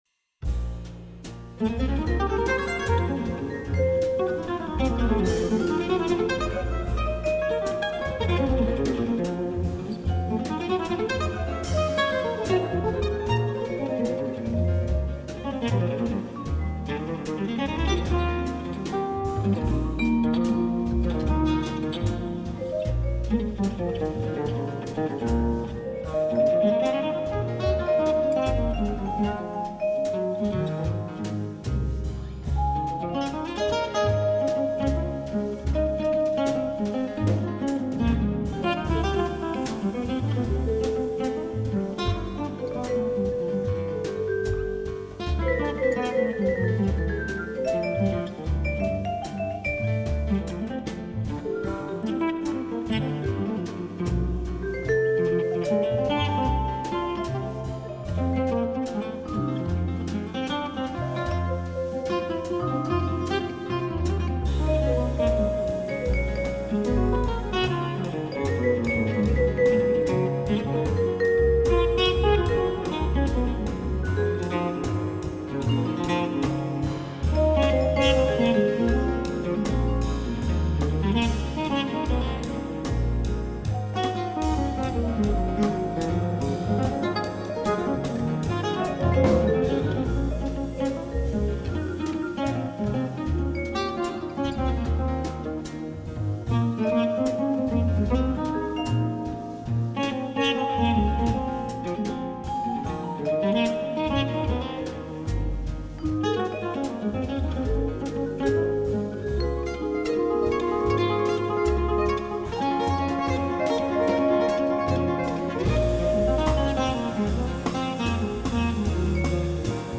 ニューエイジ
元の音源が、実際に演奏された楽器の音を使っているので、いつもよりはリアルな演奏になったかと思います。